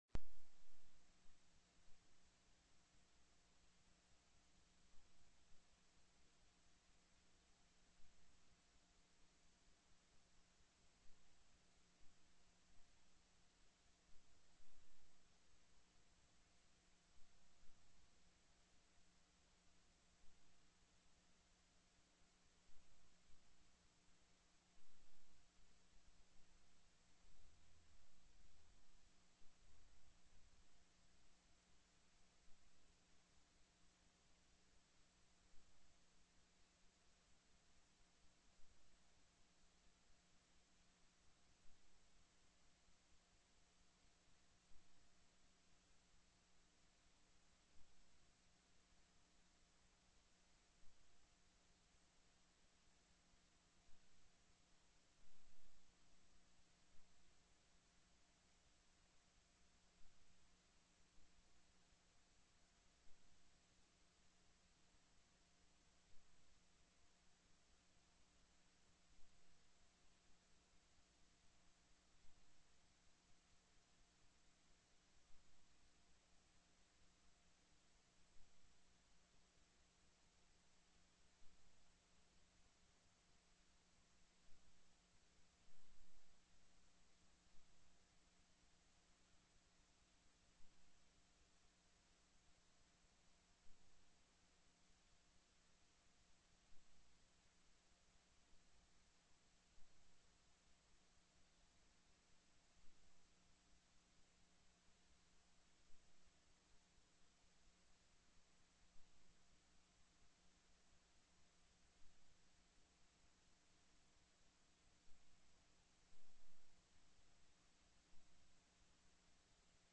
Co-Chair Stedman called the Senate Finance Committee meeting to order at 1:34 p.m.